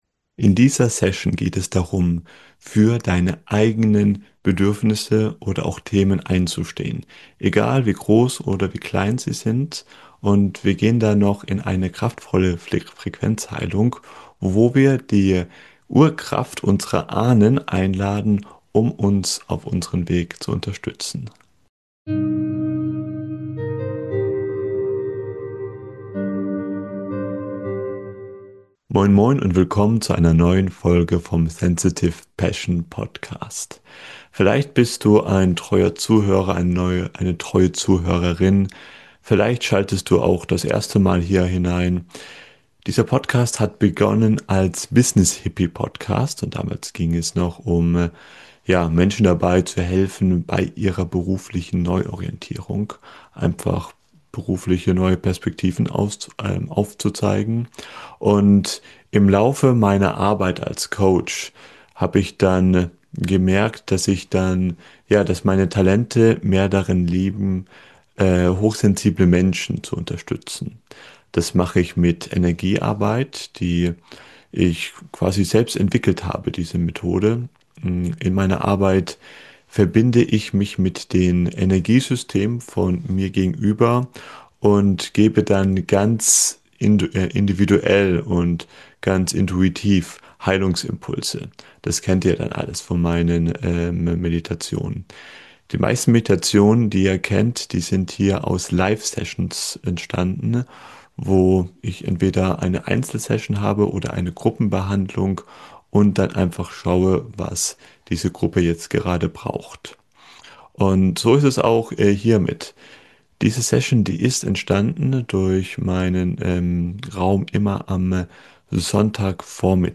#141 Verbindung mit der weiblichen Ahnenkraft - Mit Frequenz Meditation ~ BusinessHippie. Dein Podcast für berufliche Klarheit Podcast